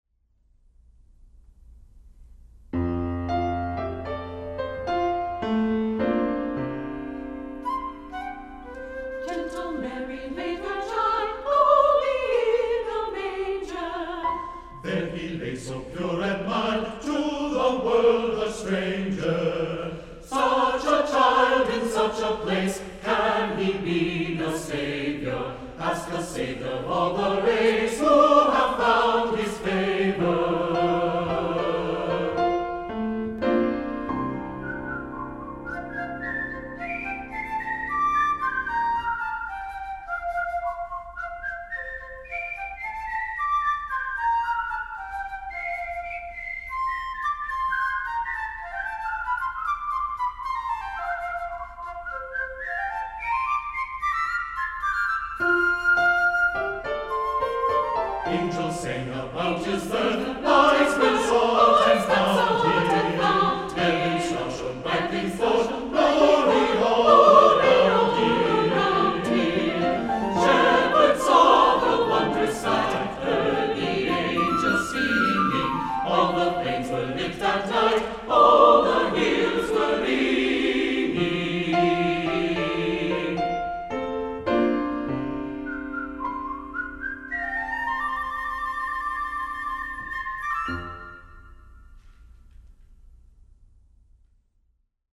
Voicing: 2-part or SATB